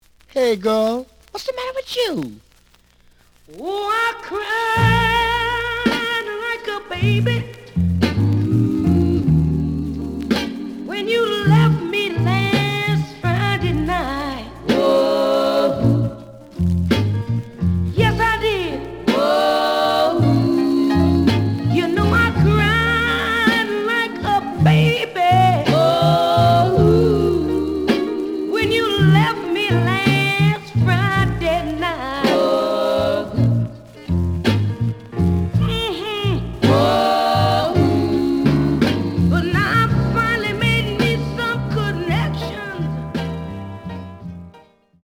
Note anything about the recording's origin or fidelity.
The audio sample is recorded from the actual item. Some noise on A side.)